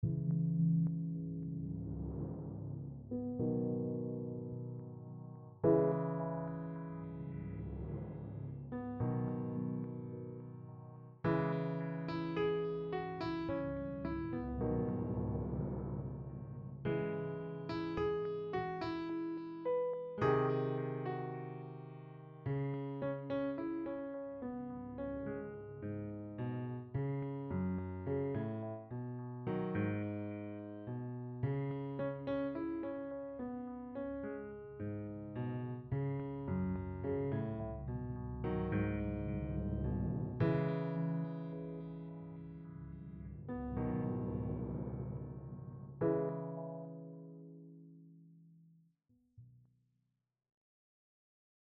Piano & Timpani
A short tune written in a Jazz Standard style.
Timpani-Piano-Stereo-Out-02.mp3